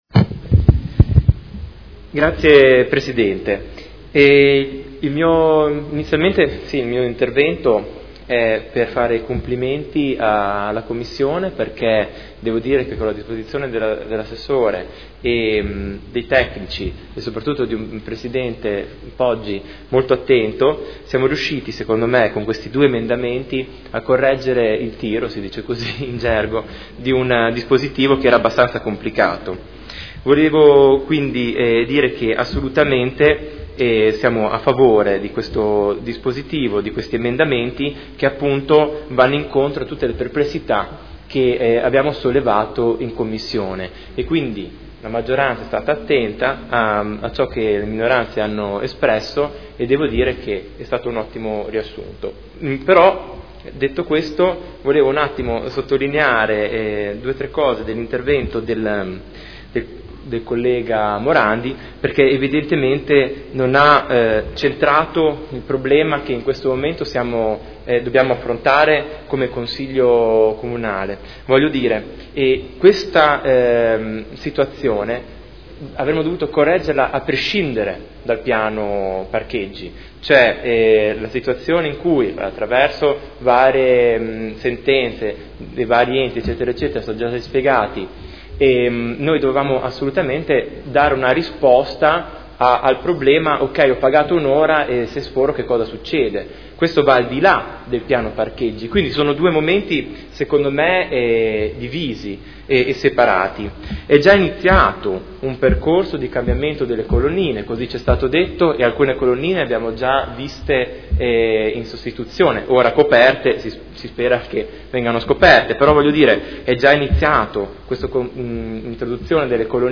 Marco Chincarini — Sito Audio Consiglio Comunale
Seduta del 09/07/2015 Dibattito. Approvazione del Regolamento per l’utilizzo delle aree di parcheggio a pagamento su strada.